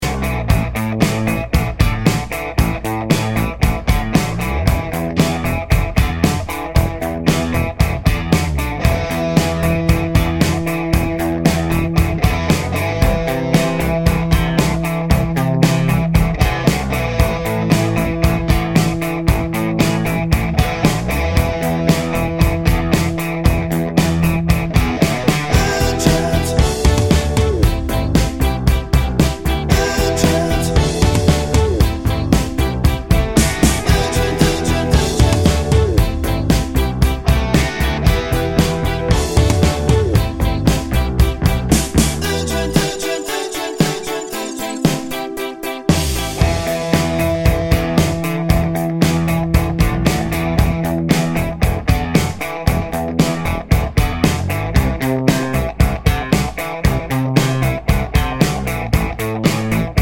Minus Sax Soft Rock 4:18 Buy £1.50